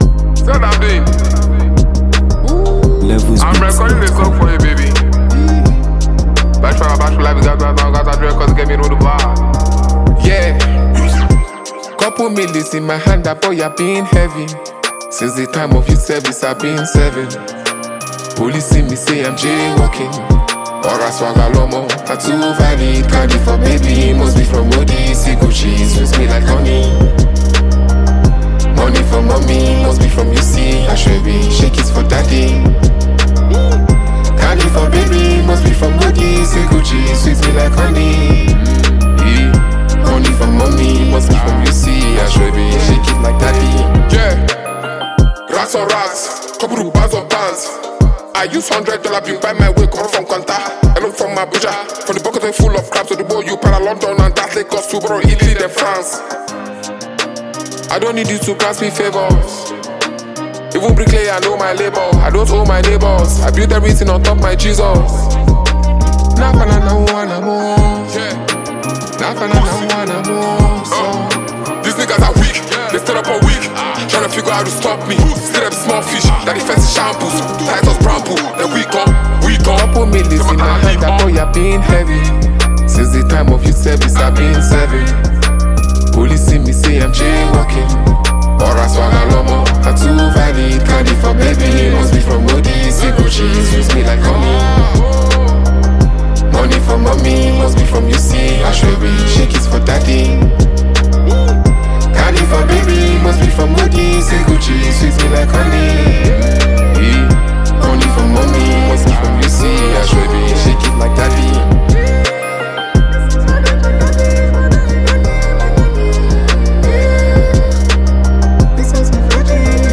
Nigeria Music
a refreshing fusion of Afro-rap and urban hip-hop